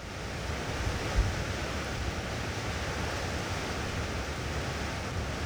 wind.wav